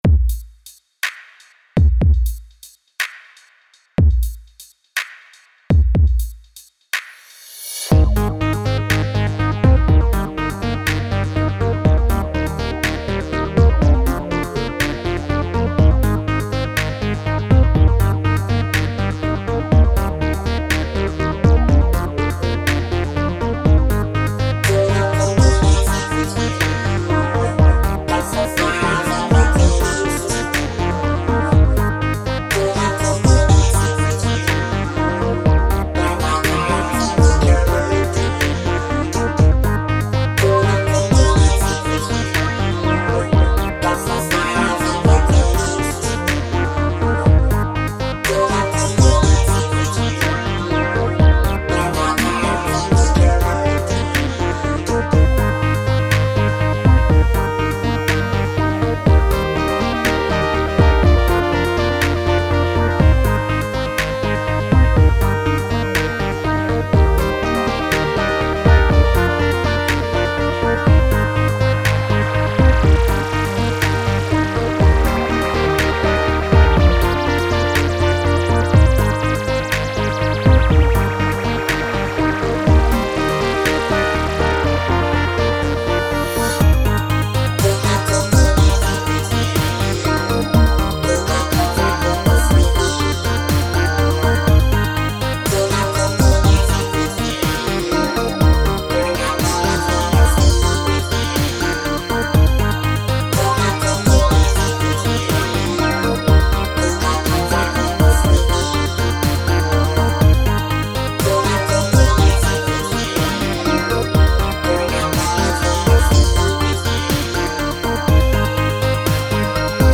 And I love the mighty remix.